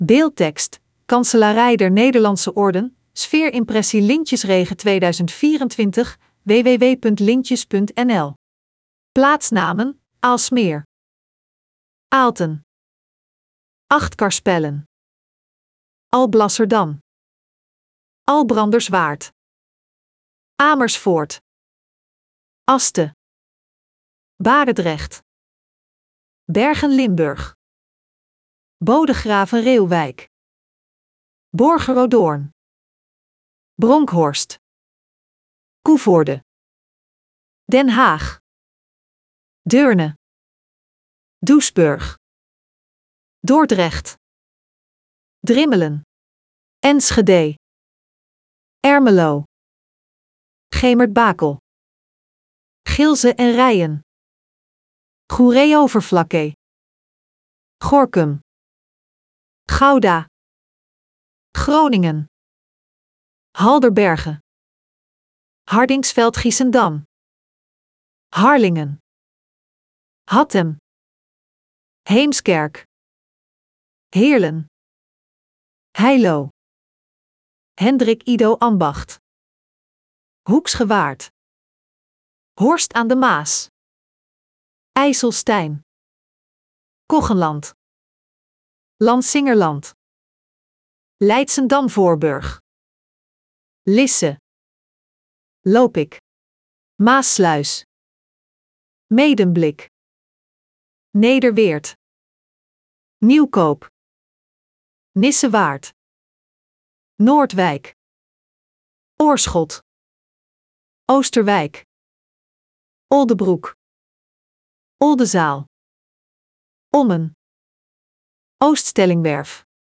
Een sfeerimpressie van de lintjesregen 2024